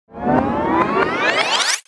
جلوه های صوتی
دانلود آهنگ تلویزیون 2 از افکت صوتی اشیاء
دانلود صدای تلویزیون 2 از ساعد نیوز با لینک مستقیم و کیفیت بالا